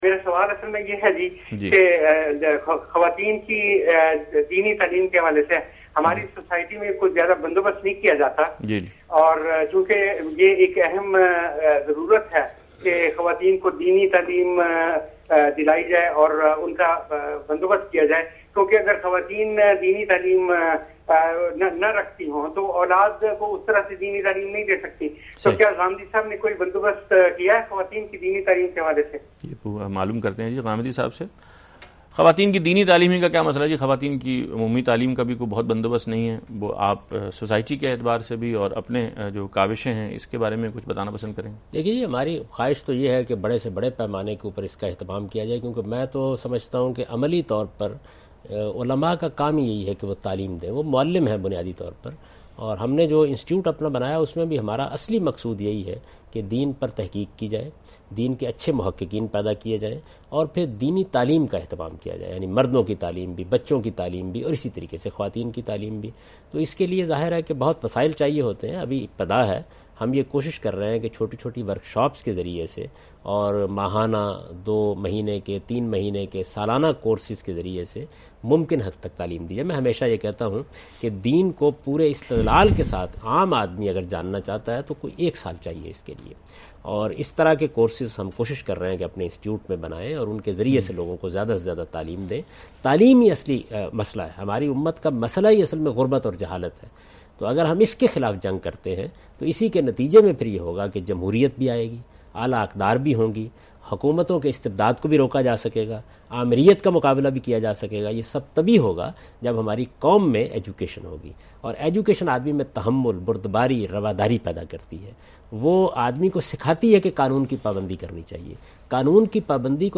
Category: TV Programs / Aaj Tv / Miscellaneous /
Question and Answers with Javed Ahmad Ghamidi in urdu, discussions_women